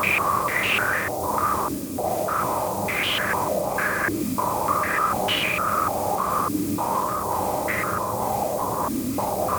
STK_MovingNoiseE-100_02.wav